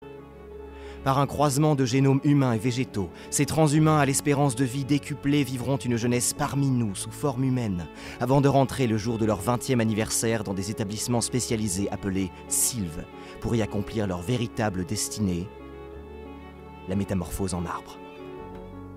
voix
Publicité - Voix OFF Narration